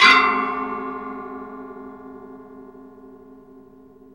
METAL HIT 7.wav